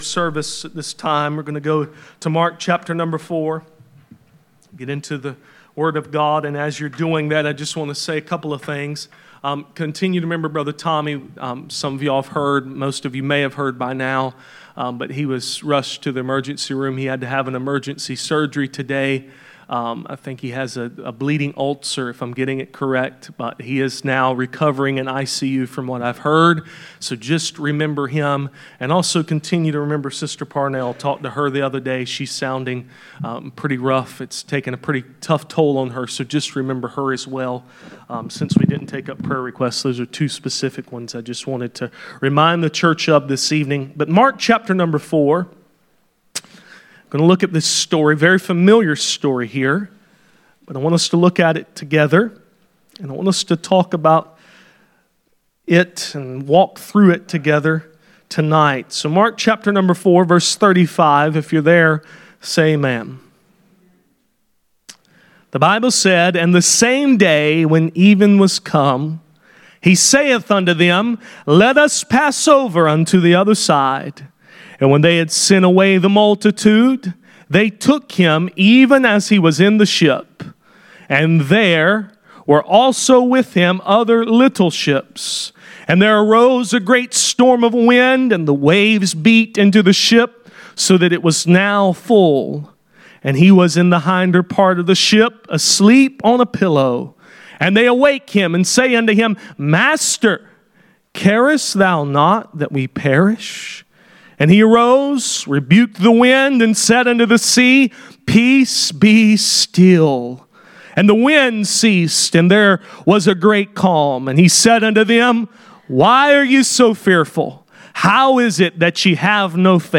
Miraculous moments with the master Passage: Mark 4:35-41 Service Type: Sunday Evening « Learning to fish from a carpenter More than Conquerors